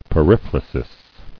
[pe·riph·ra·sis]